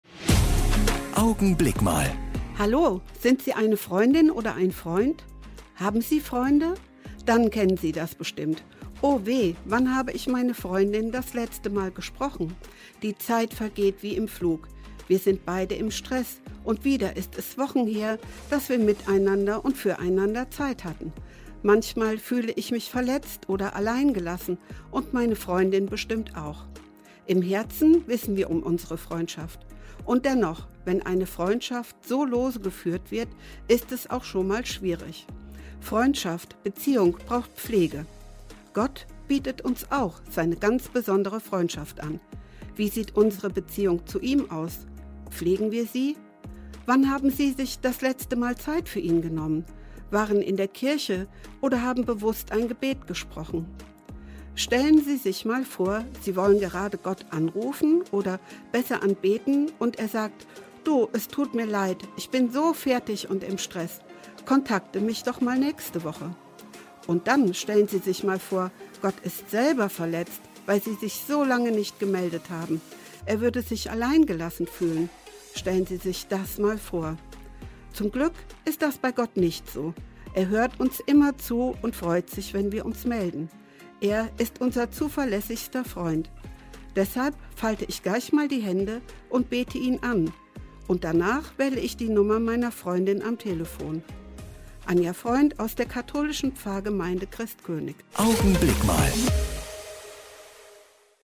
Augenblick Mal - Die Kurzandacht im Radio
Jeden Sonntag gegen halb neun bei Radio Siegen zu hören: Die Kurzandacht der Kirchen (evangelisch und katholisch) - jetzt auch hier im Studioblog zum Nachhören.